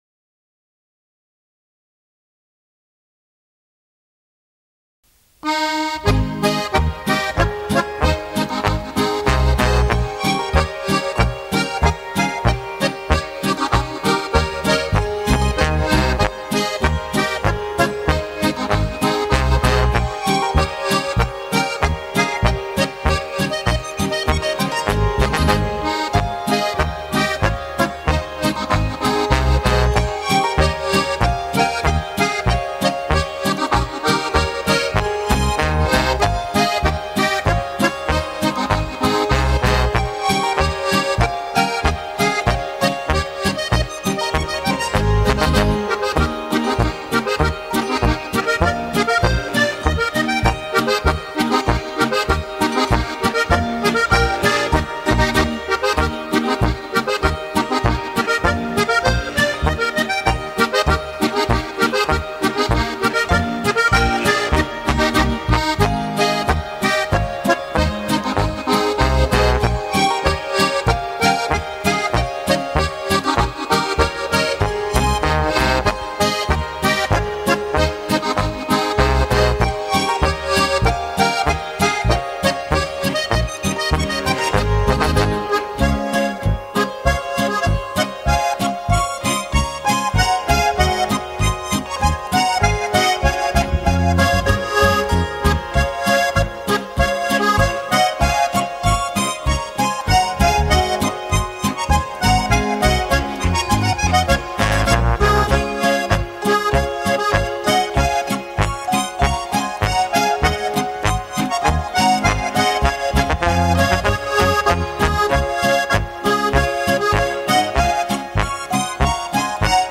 Harmonika